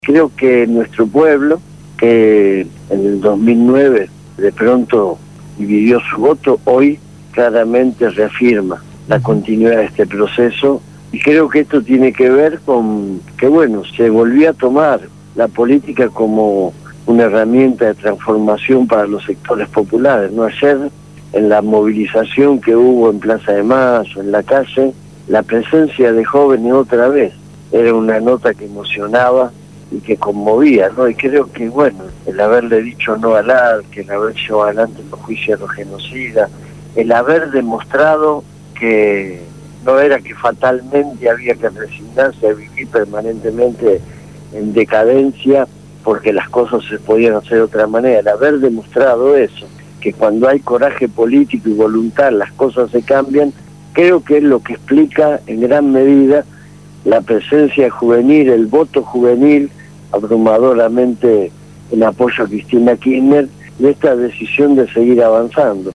Entrevista a Hugo Yasky, Secretario General CTA de los Trabajadores